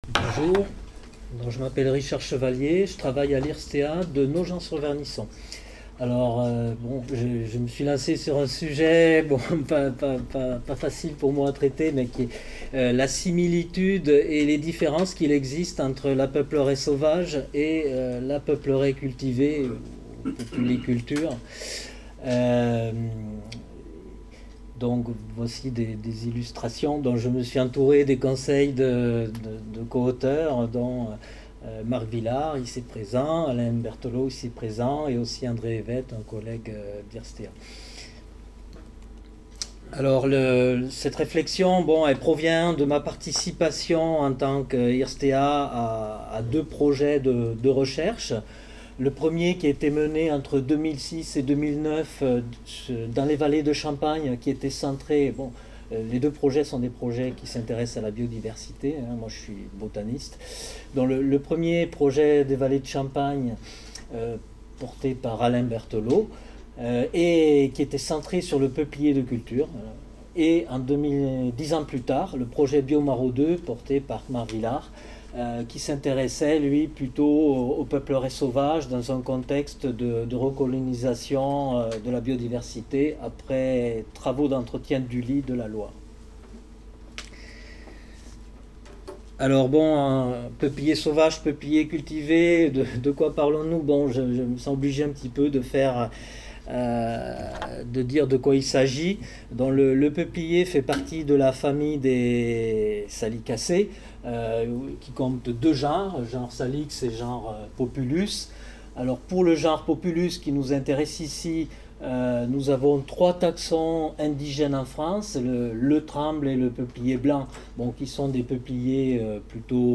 Séminaire organisé dans le cadre du projet « Reforesté »